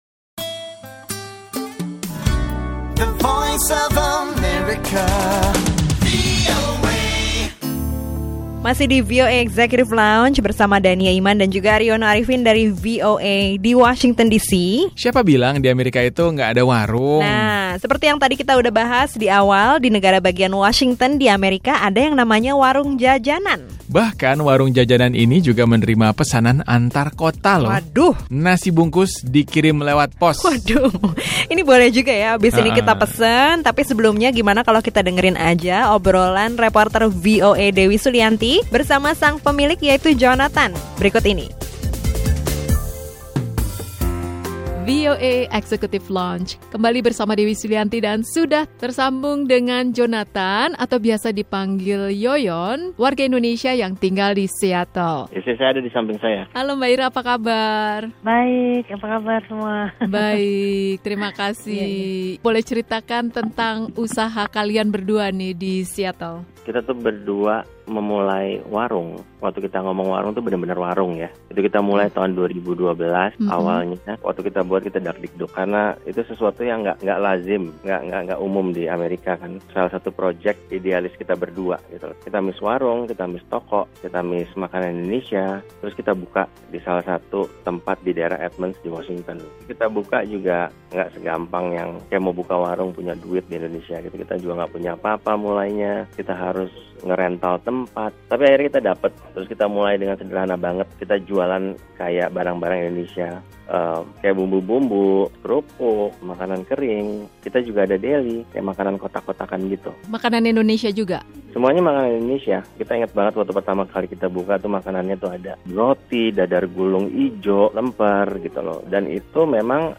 Obrolan bersama diaspora Indonesia yang memiliki usaha toko dan rumah makan ala warung Indonesia di kota Edmonds, negara bagian Washington.